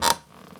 chair_frame_metal_creak_squeak_08.wav